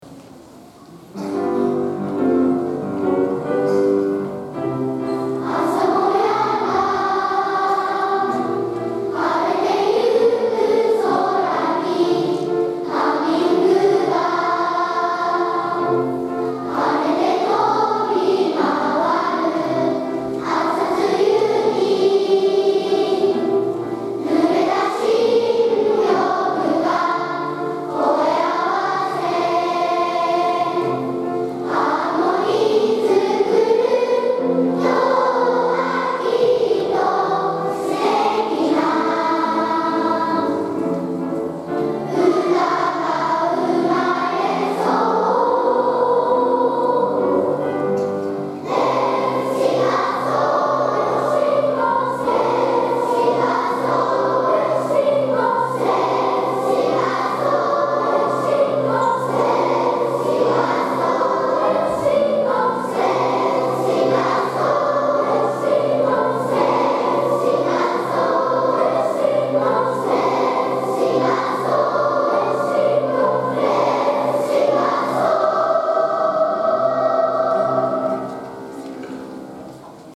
大空ありがとうコンサート
Let’s sing a song」全校３部合唱です。最初は緊張していましたが会場のみなさんにあたたかく見守られていると感じると、子どもたちの顔もだんだん笑顔になって楽しく元気いっぱいに歌うことができました！